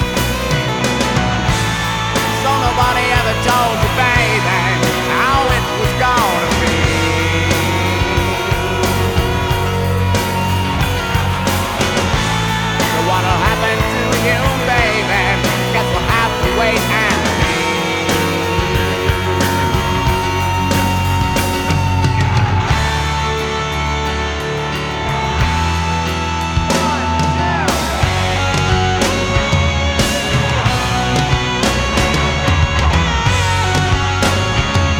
Жанр: Пост-хардкор / Хард-рок / Рок